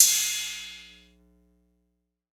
Crashes & Cymbals
MZ Crash [Oz].wav